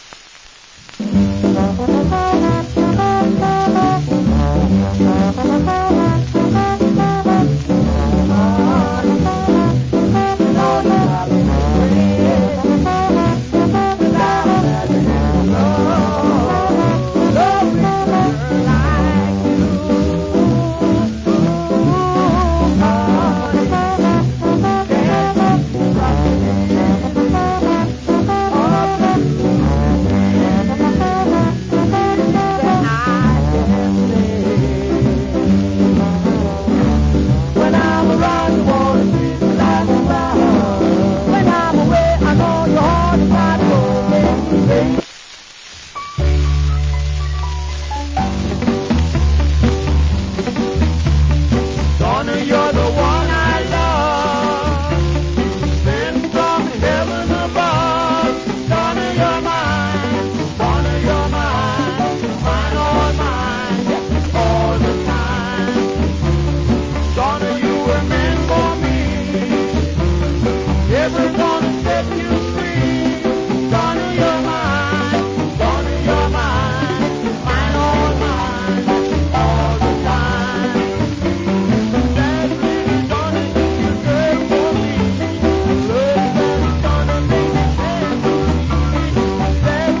Early 60's. Nice Duet Shuffle Vocal.